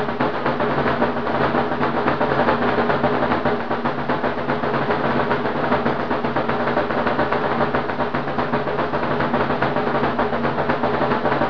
lambeg1.wav